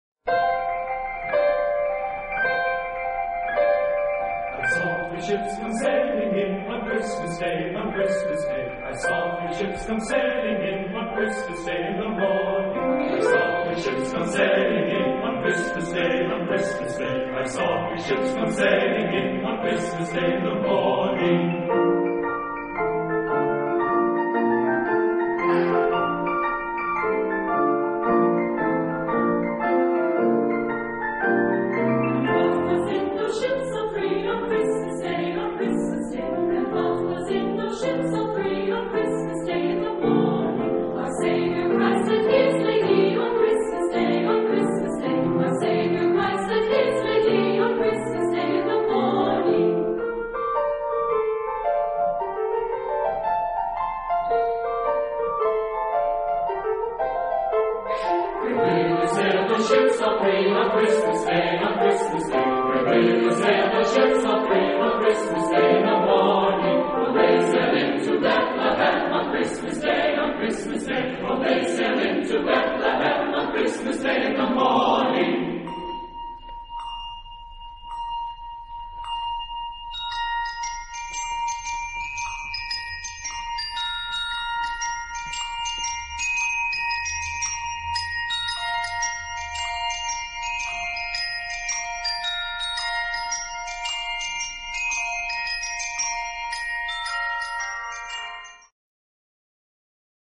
SSATB (5 voces Coro mixto) ; Coro y organo.
Canción de Navidad.
Carol.
subject Texto subject Texto Pronunciación language Traducción unfold_more Francés Alemán volume_down Extr. de audio por Susquehanna Chorale (USA) Apoyar a Musica Enriquecer la ficha Señalar un error Ficha No 75719